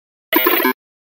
Звуки глюков
На этой странице собрана коллекция звуков цифровых глюков, сбоев и помех.